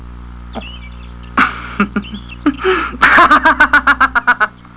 Japanese- Laughing
T__laughs_.wav